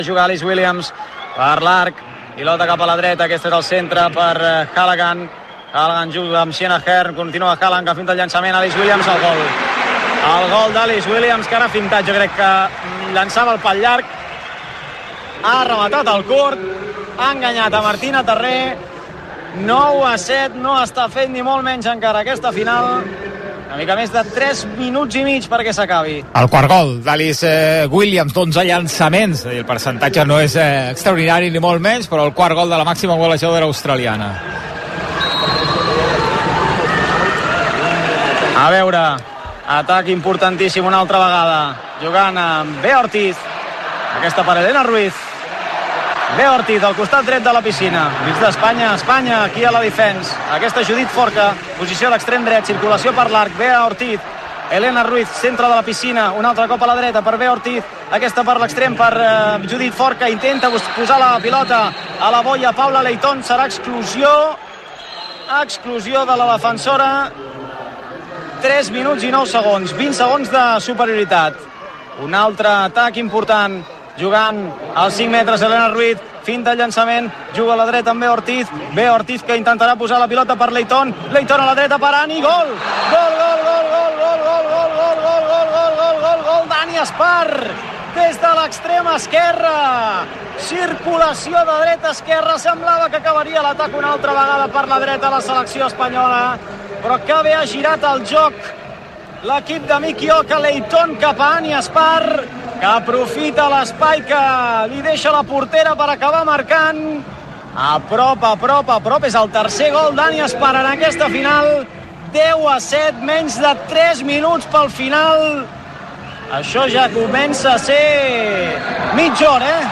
Narració del partit de la final de waterpolo femení dels Jocs Olímpics de París entre Espanya i Austràlia.
Narració dels tres minuts finals del partit i de la victòria de la selecció femenina d'Espanya a Austràlia per 11 a 9.